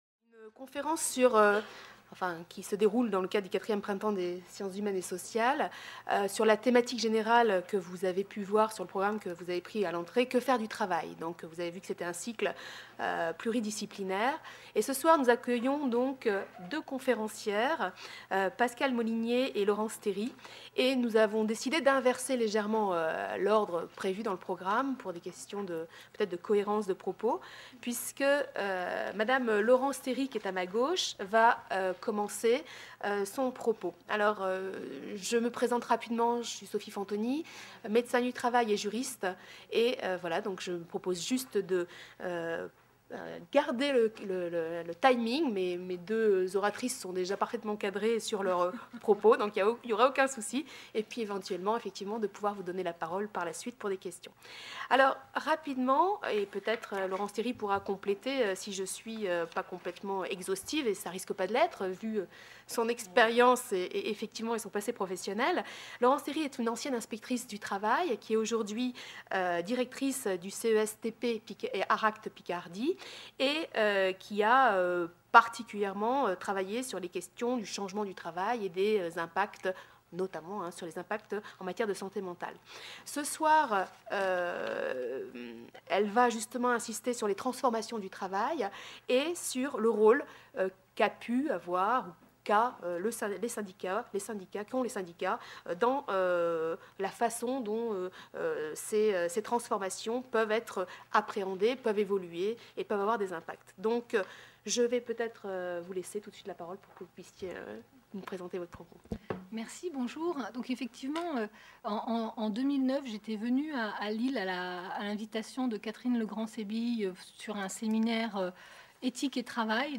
Conférences